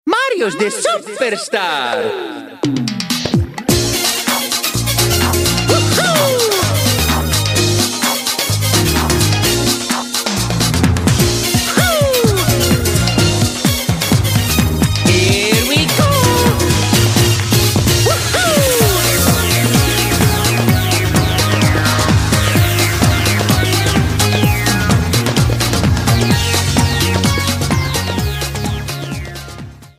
Trimmed and fadeout
Fair use music sample